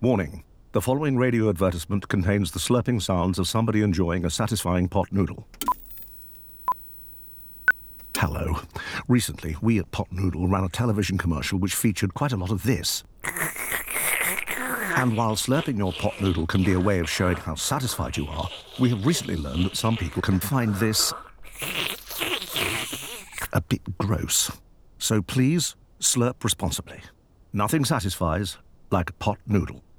Following the launch, some viewers have taken to X to express their disgust for the new ad – Pot Noodle has quickly pivoted with an alternative version for them, replacing the slurping, with more bearable sound effects.
POT-NOODLE-APOLOGY-30-RADIO.wav